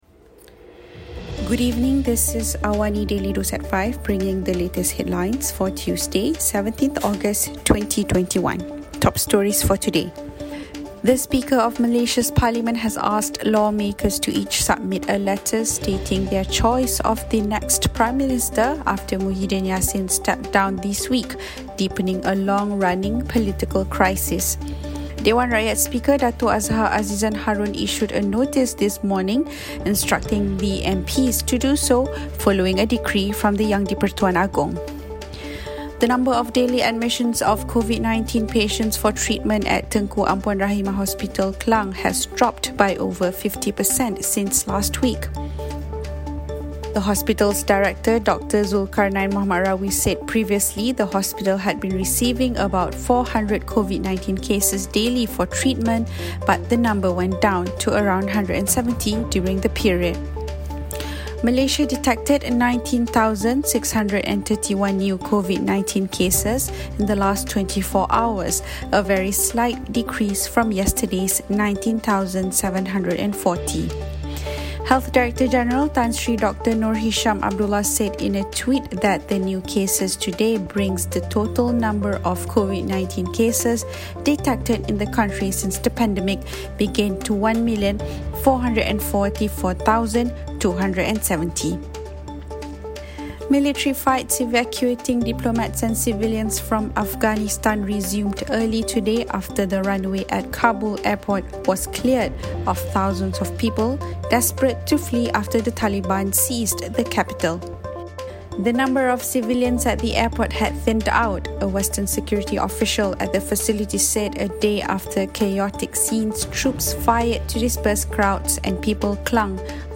Listen to the top stories of the day, reporting from Astro AWANI newsroom — all in 3 minutes.